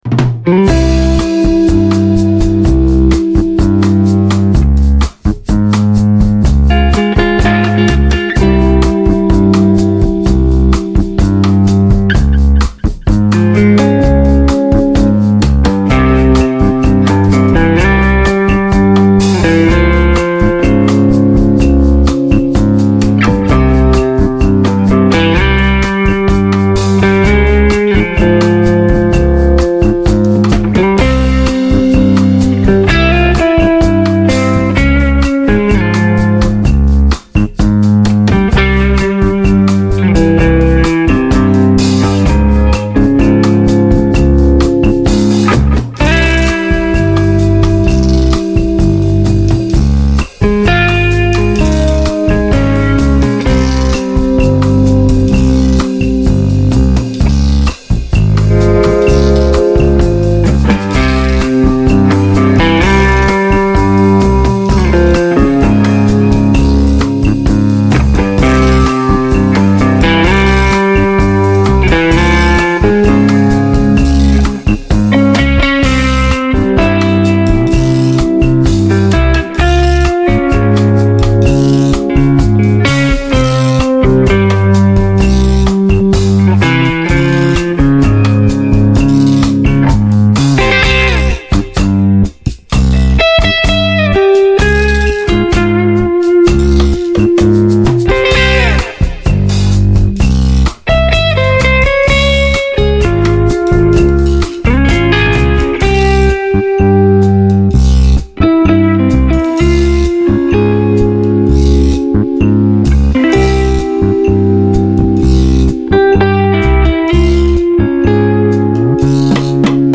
描述：爵士与布鲁斯|激越
标签： 贝司 电吉他
声道立体声